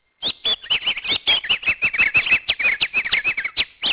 鳥語